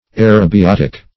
Aerobiotic \A`["e]r*o*bi*ot"ic\ (?; 101), a. (Biol.)